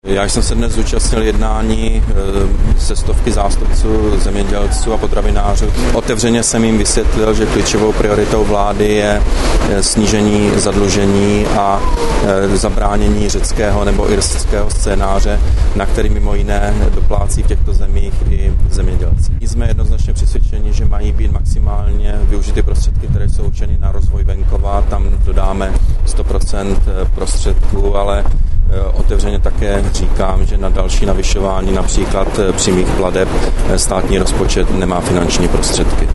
Komentář Petra Nečase k jednání se zemědělci